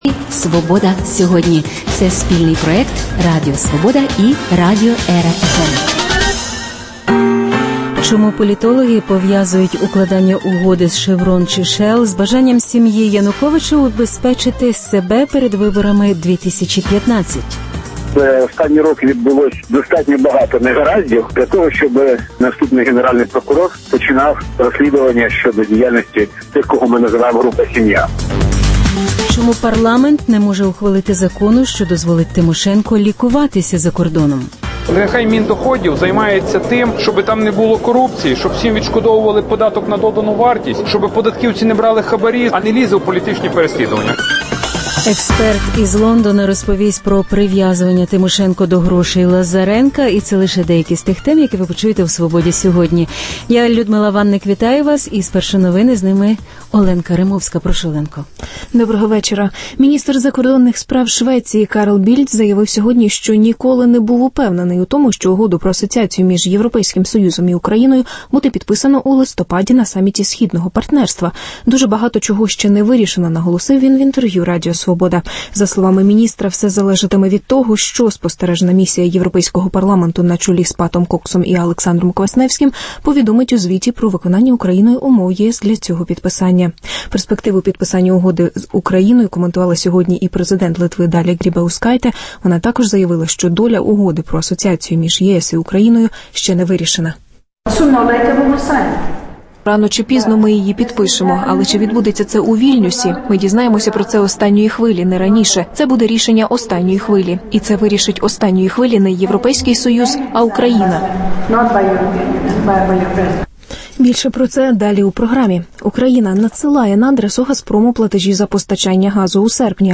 Чому парламент не може ухвалити закону, що дозволить Тимошенко лікуватися за кордоном? Прив’язування Тимошенко до грошей Лазаренка – інтерв’ю з експертом із Лондона 45 років минуло з дня самоспалення на Хрещатику Василя Макуха, колишнього політв’язня і вояка УПА.